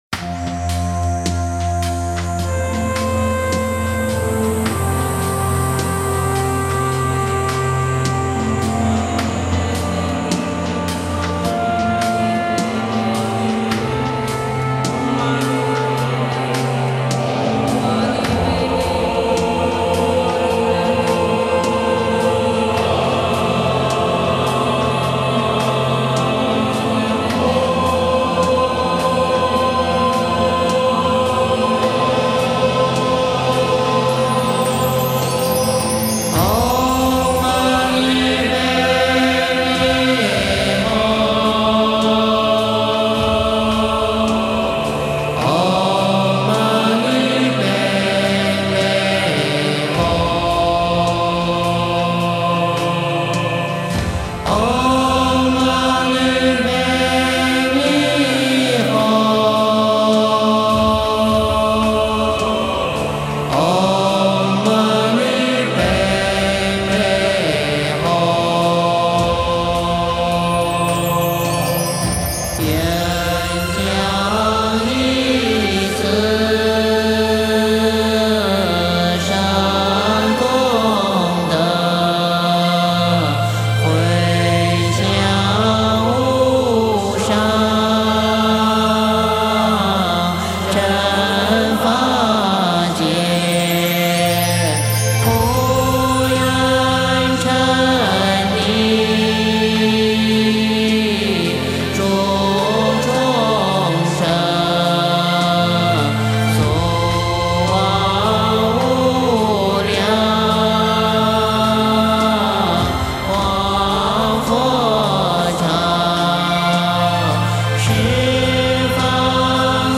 梵呗 | 回向的意义